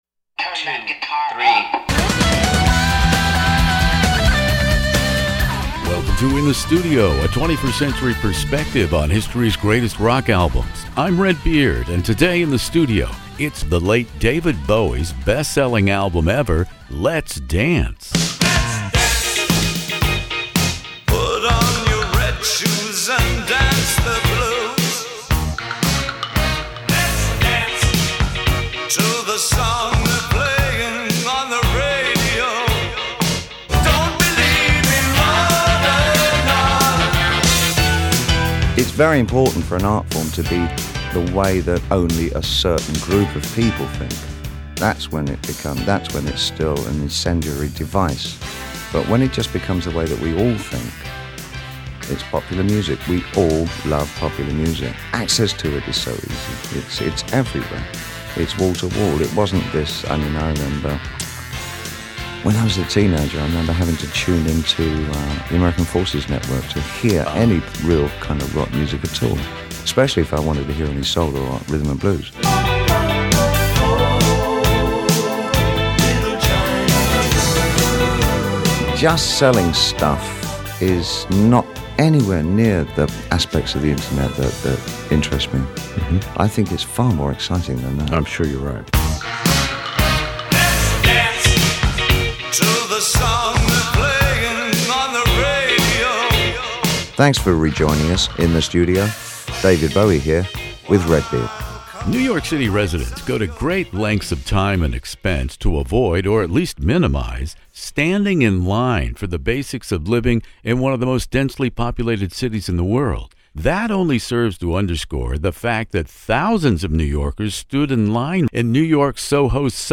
David Bowie "Let's Dance" interview